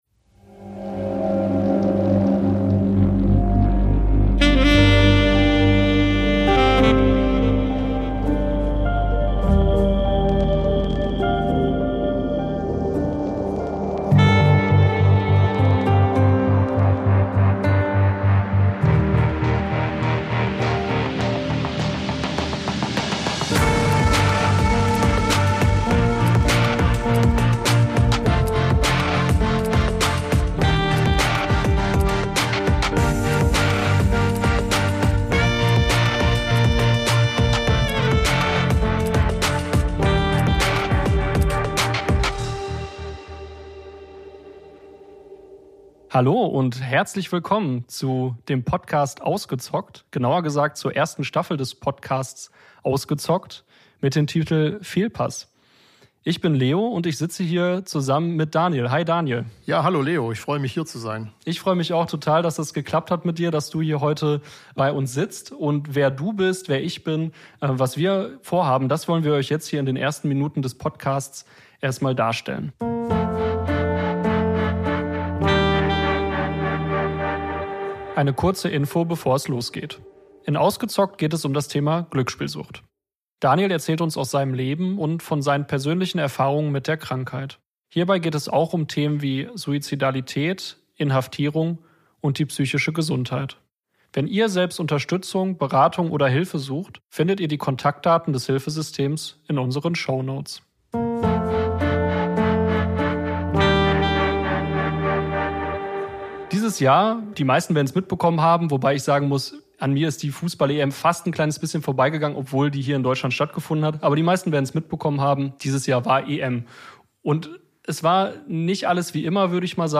Folge 1: Die Anfänge ~ Ausgezockt: Sucht ungefiltert - Betroffene im Gespräch Podcast
Außerdem hat Burkhard Blienert, der Sucht- und Drogenbeauftrage der Bundesregierung uns ein Interview gegeben.